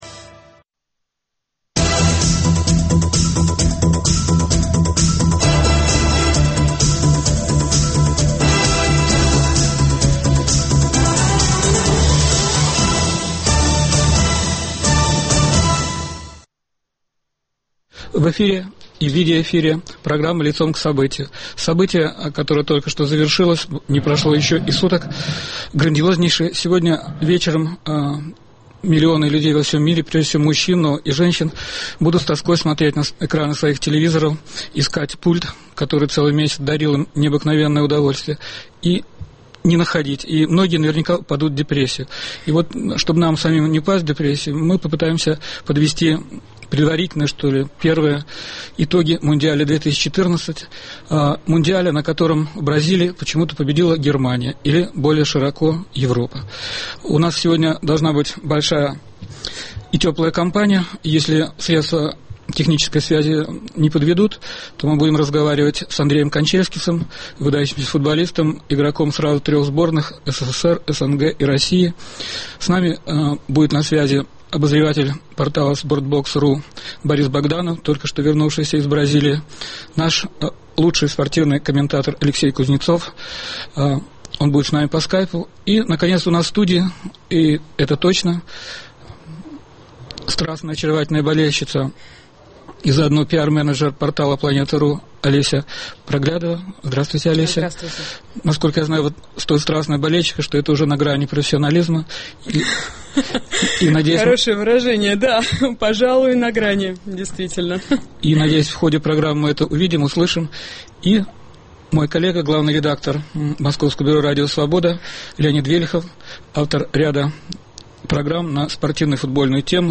Почему в Бразилии победила Германия? Обсуждают - игрок сборных СССР, СНГ и России Андрей Канчельскис и журналисты